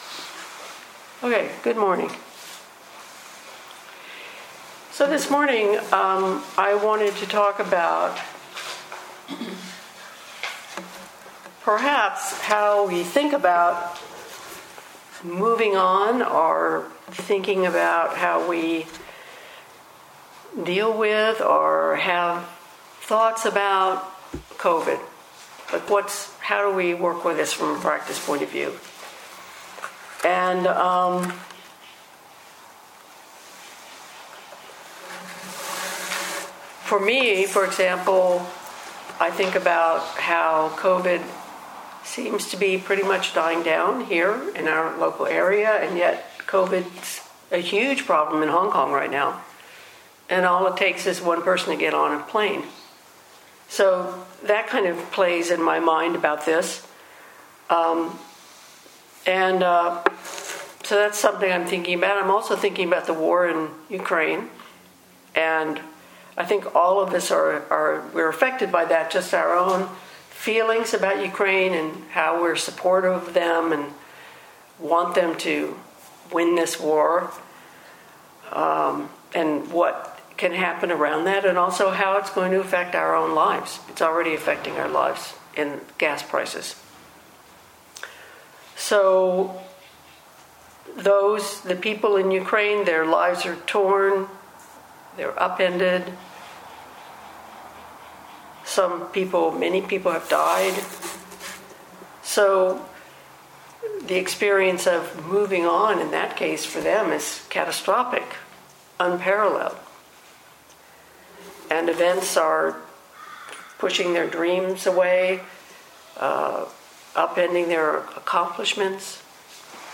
2022 in Dharma Talks